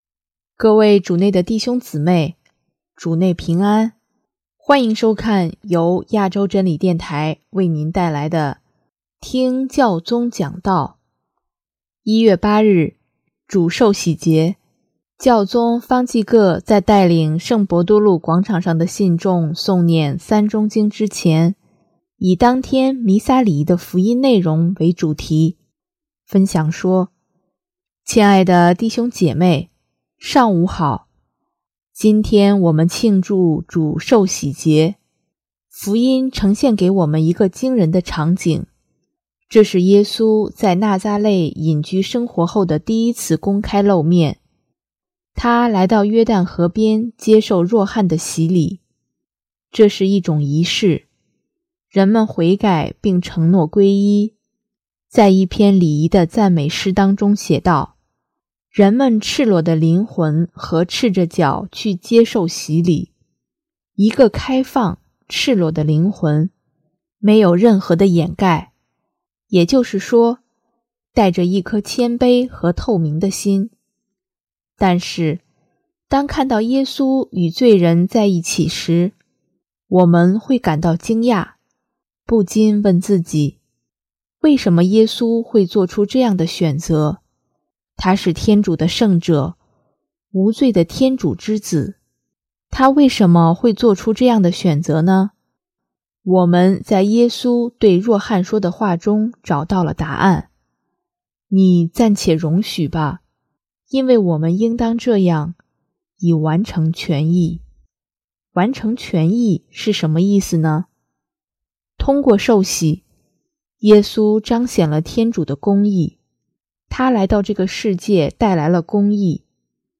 【听教宗讲道】|天主的公义不是为定人的罪，而是拯救
1月8日，主受洗节，教宗方济各在带领圣伯多禄广场上的信众诵念《三钟经》之前，以当天弥撒礼仪的福音内容为主题，分享说：